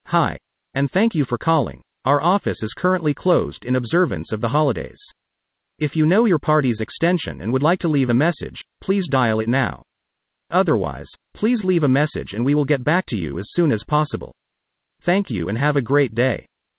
Holiday Message with Voicemail
14-Generic-Holiday-Greeting-With-VM.wav